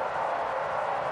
snd_paper_surf.ogg